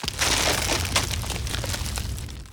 Ice Wall 1.wav